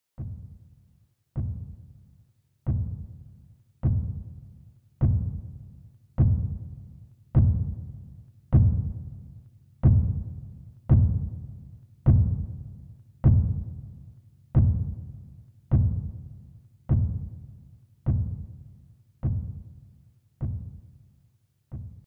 Звуки топота
Громкий топот ног из соседней комнаты